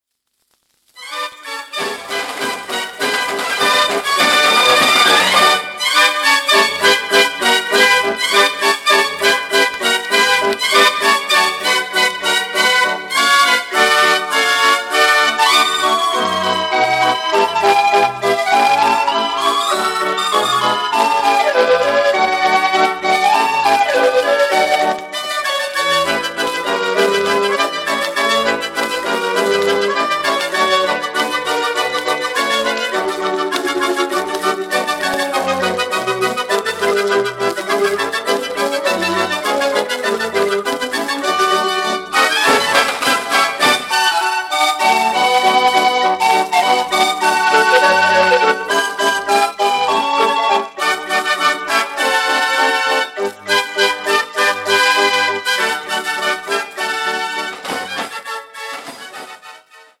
Deze mix laat goed horen hoe draaiorgels
destijds populaire muziek vertaalden naar hun eigen klank.
Formaat 78 toerenplaat, 10 inch